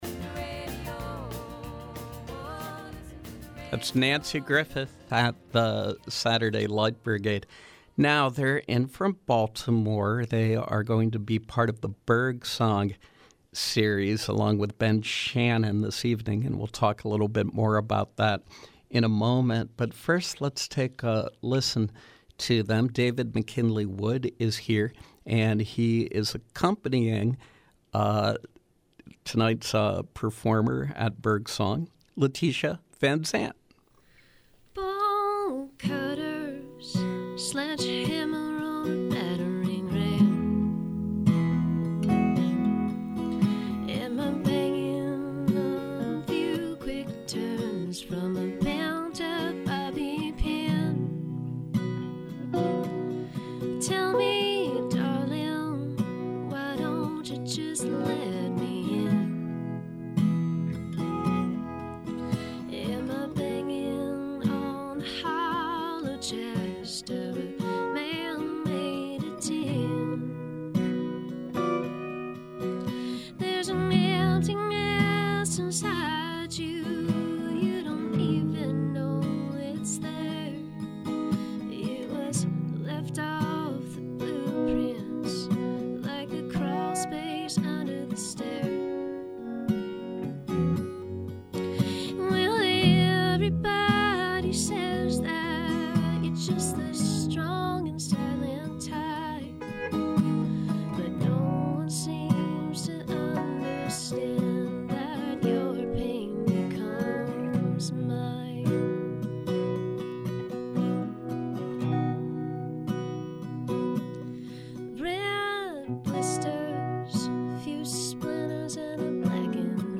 Baltimore singer-songwriter